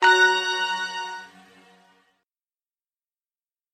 На этой странице собраны звуки электронной почты: уведомления о новых письмах, отправке сообщений и другие сигналы почтовых сервисов.
Звуковое оповещение о новом письме в Windows